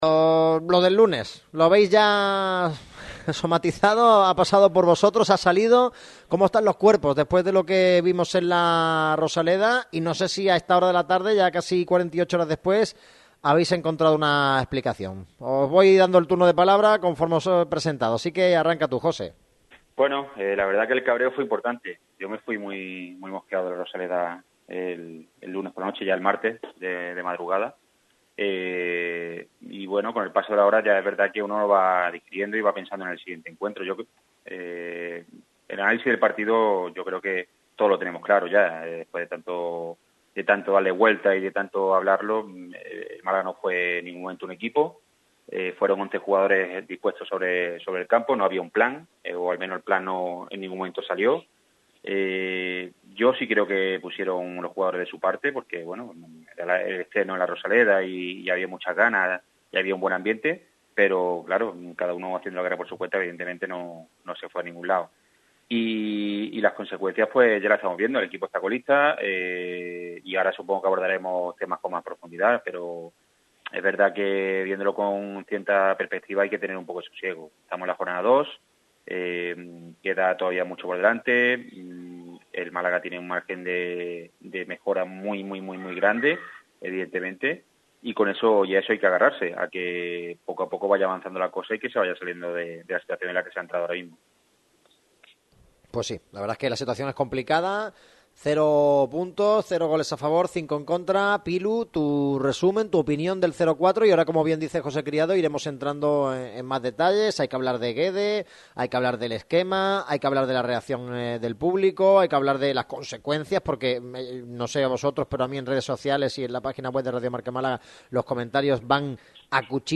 La crisis deportiva del Málaga, a debate